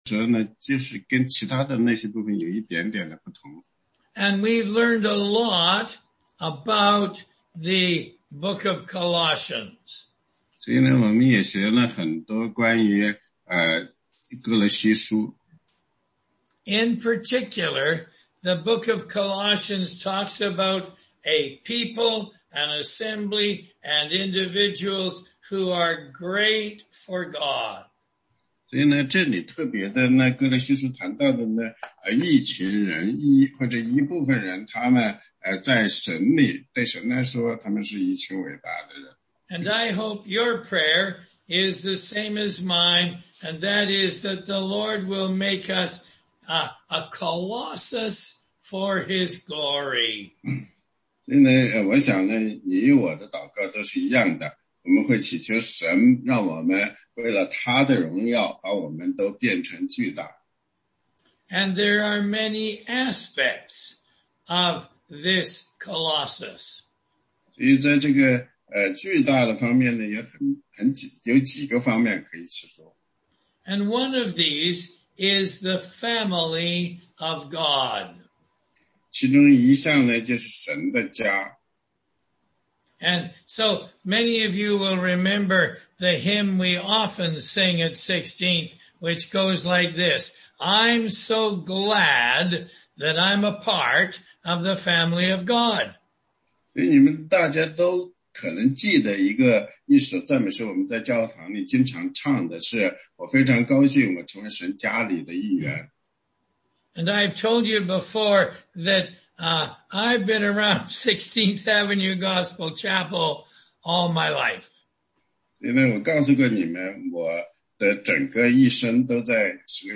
16街讲道录音 - 歌罗西书4章7-18节：神的大家庭
答疑课程